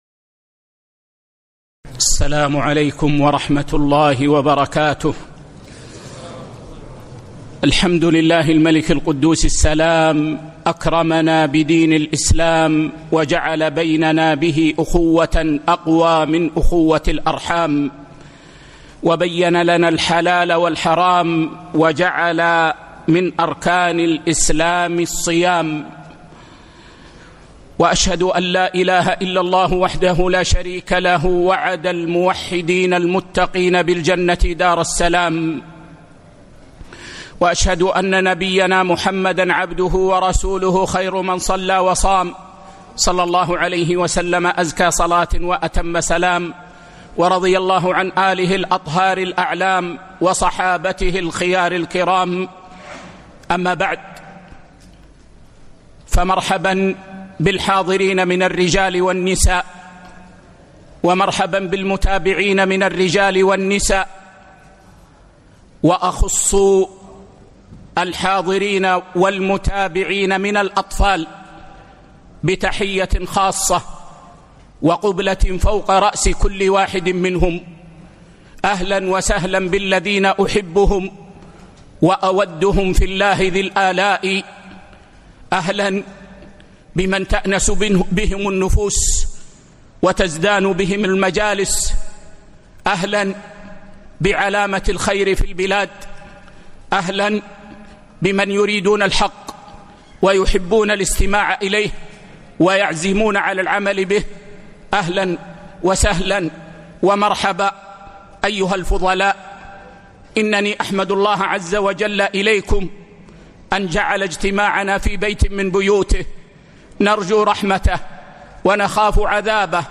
محاضرة: أثر الأمن على العبادات | بدولة الإمارات 1446/9/9 هـ